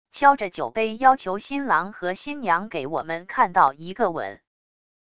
敲着酒杯要求新郎和新娘给我们看到一个吻wav音频